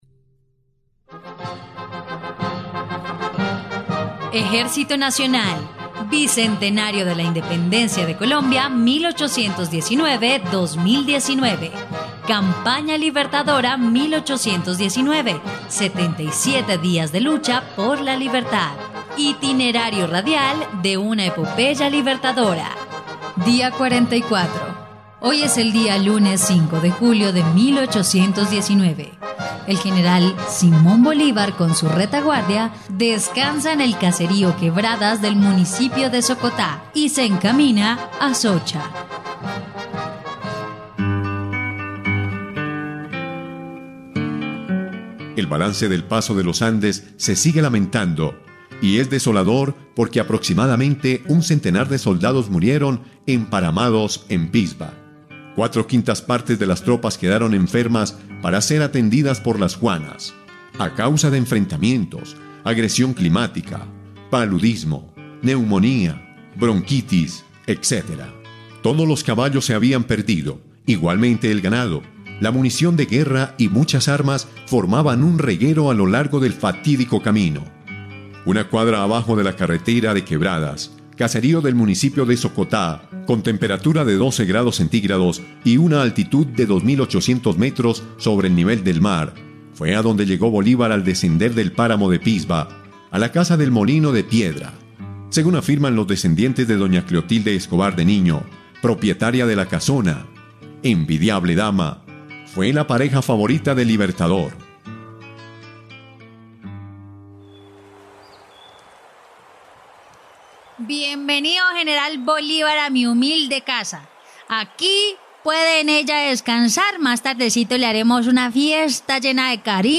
dia_44_radionovela_campana_libertadora.mp3